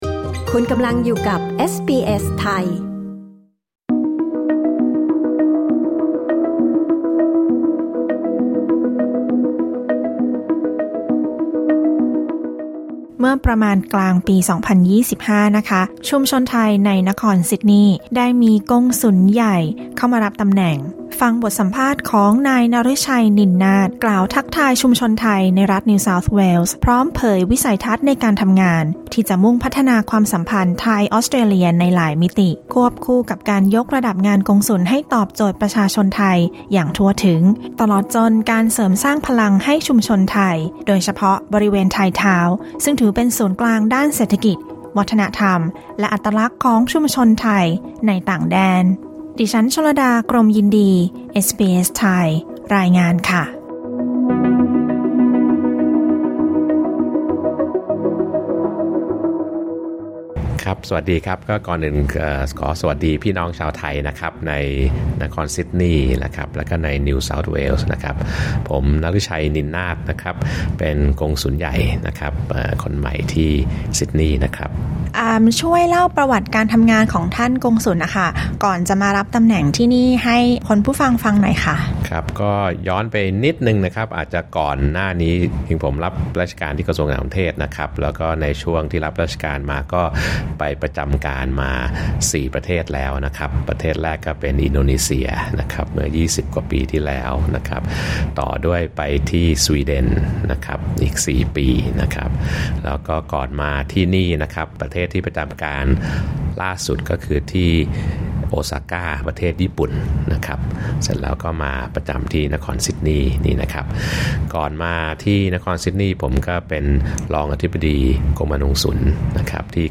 เอสบีเอสไทยสัมภาษณ์กงสุลใหญ่ฯ ถึงวิสัยทัศน์ในการทำงานเพื่อส่งเสริมความสัมพันธ์ไทย-ออสเตรเลีย และภารกิจการทำงานเพื่อชุมชนไทยในรัฐนิวเซาท์เวลส